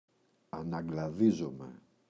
αναγκλαδίζομαι [anangla’ðizome] – ΔΠΗ